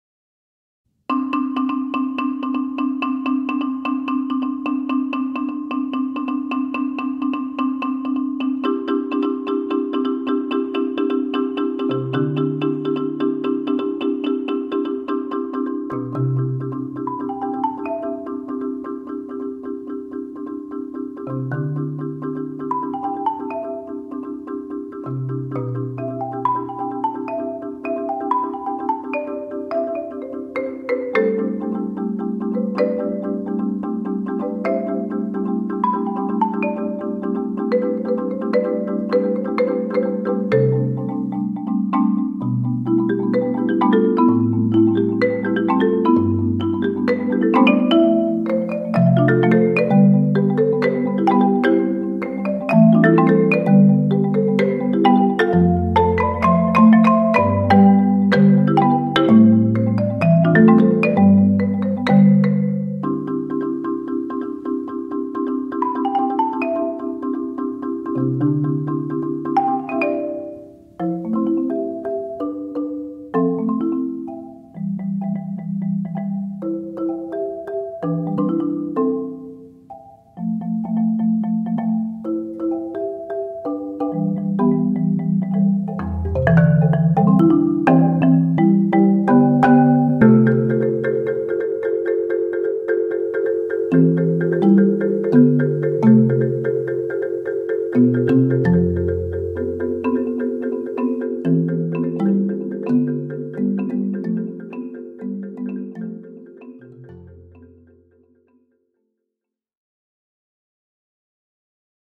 Voicing: Marimba Quartet